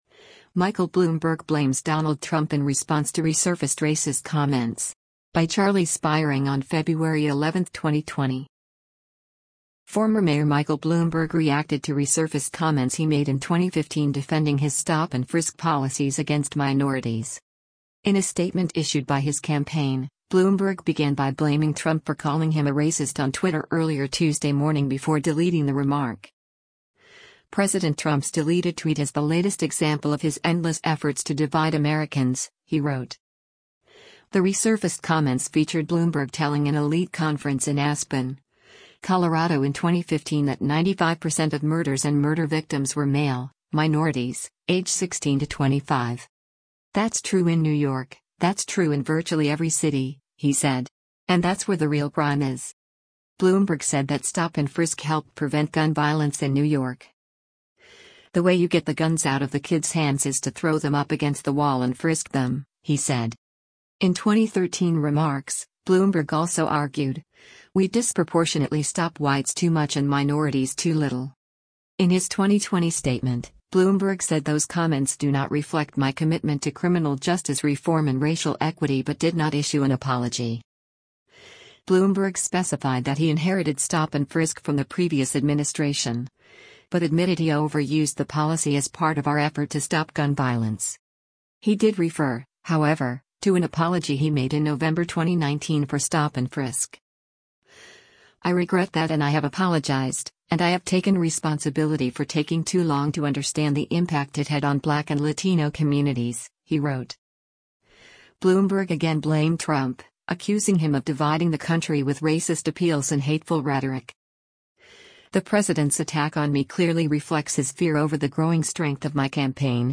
The resurfaced comments featured Bloomberg telling an elite conference in Aspen, Colorado in 2015 that 95 percent of murders and murder victims were “male, minorities, age 16-25.”